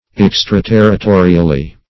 Meaning of extraterritorially. extraterritorially synonyms, pronunciation, spelling and more from Free Dictionary.
extraterritorially.mp3